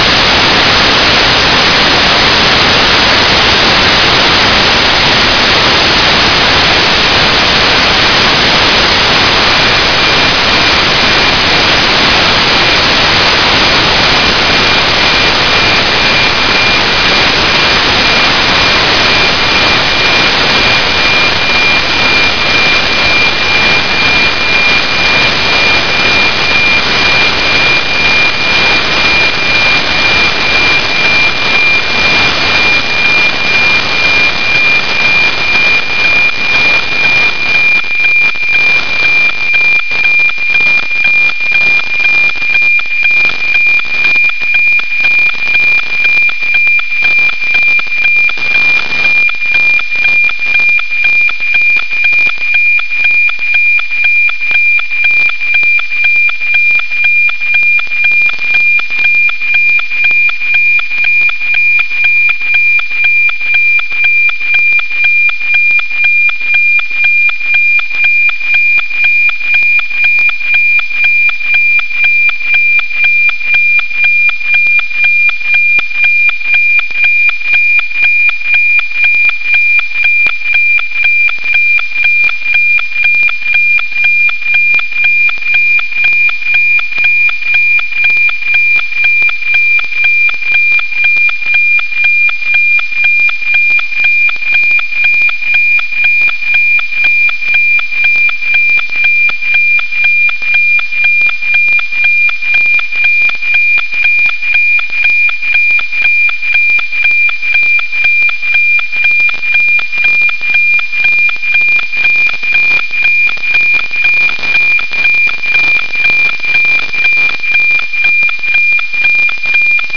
Die Audio-Datei des letzten NOAA15-Empfangs ist hier zu finden: Link zur WAVE-Datei Damit bleiben nun nur noch die russischen Meteor-Satelliten, welche wie die NOAA-Satelliten auf 137MHz eine niedrig aufgelöste Version ihres Satellitenbildes senden.